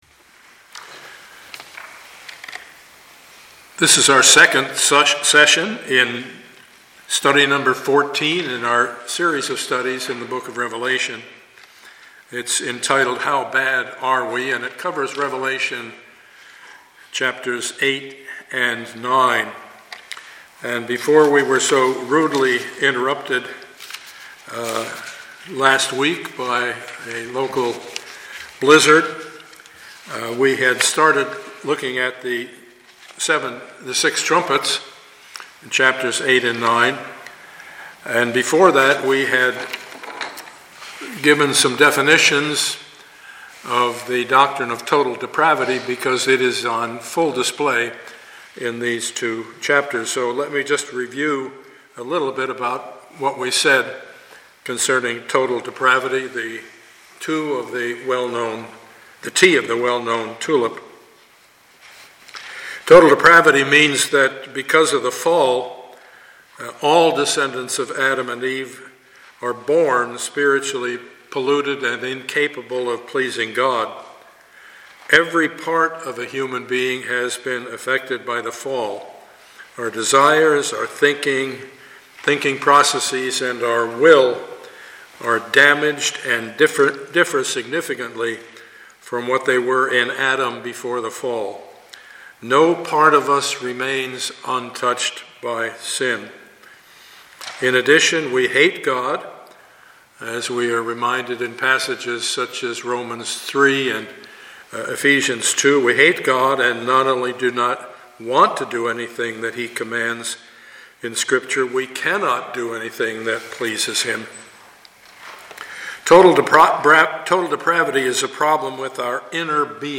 Studies in the Book of Revelation Passage: Revelation 8-9 Service Type: Sunday morning « Studies in the Book of Revelation #14A